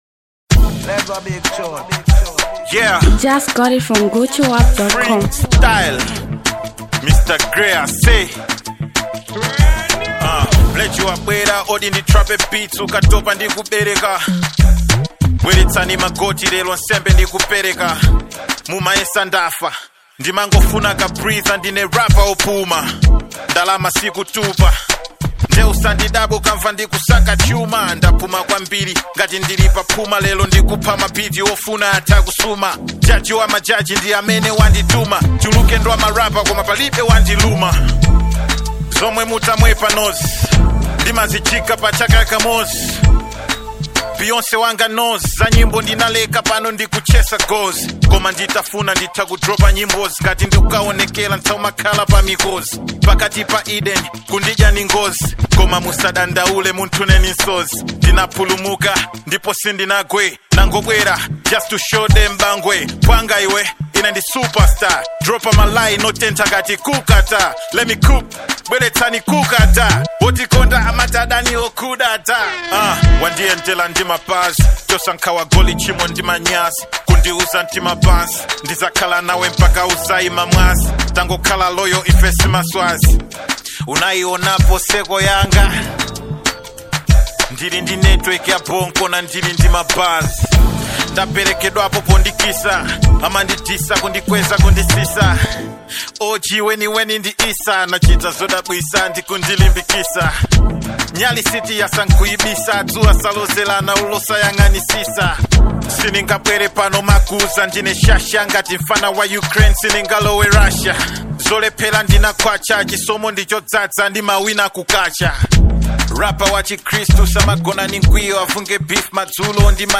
Foreign Music
Malawian renowned rap sensation artist
freestyling music joint